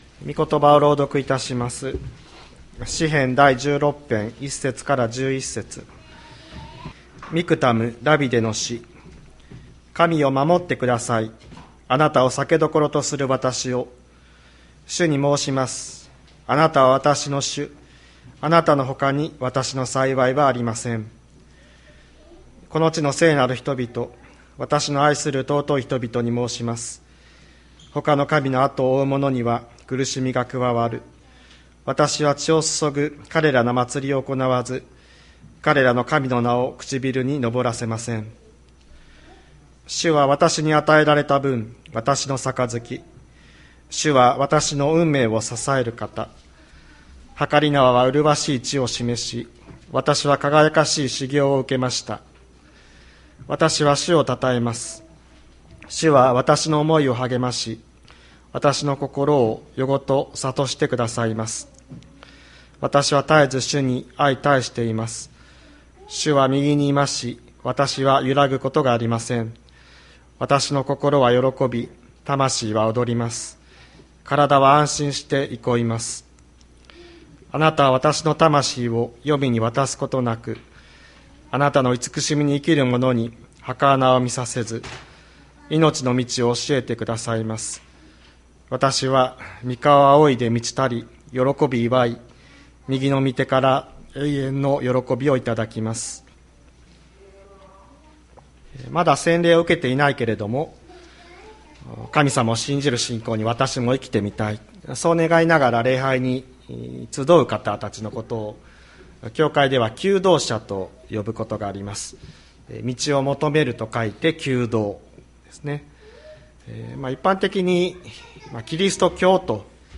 2023年03月05日朝の礼拝「神よ、守ってください」吹田市千里山のキリスト教会
千里山教会 2023年03月05日の礼拝メッセージ。